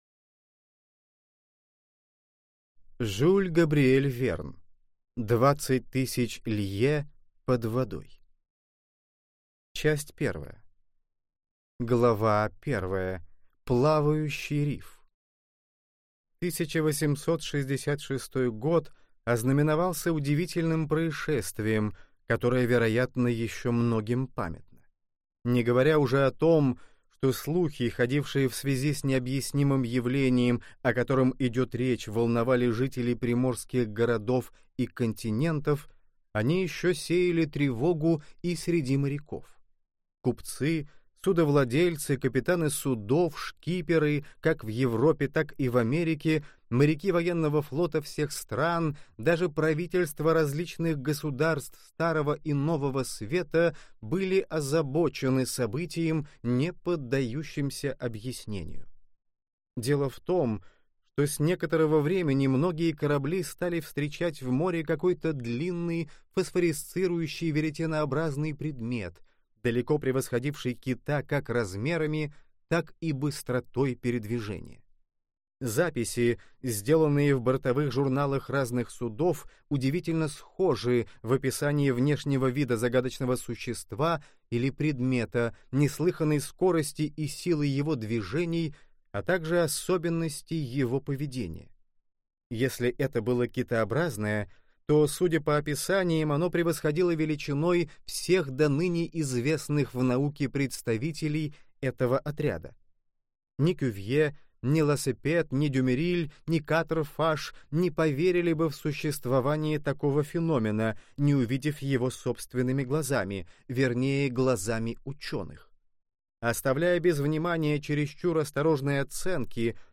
Аудиокнига Двадцать тысяч лье под водой | Библиотека аудиокниг